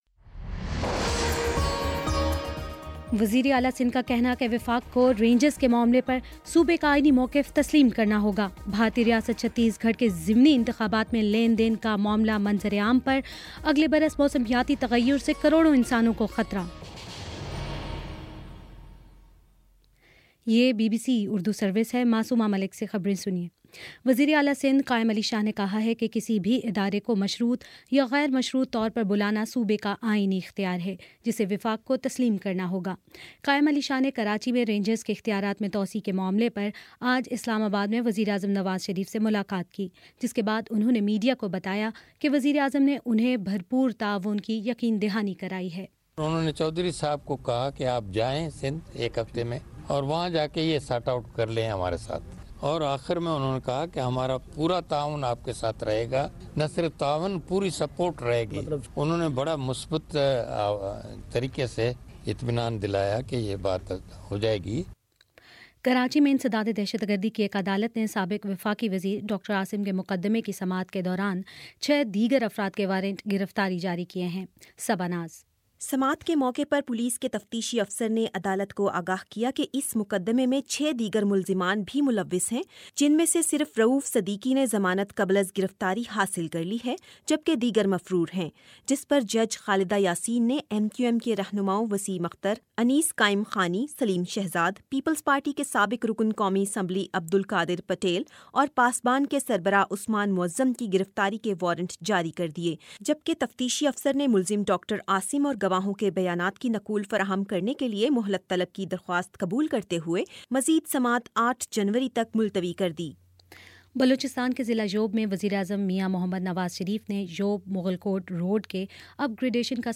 دسمبر 30 : شام چھ بجے کا نیوز بُلیٹن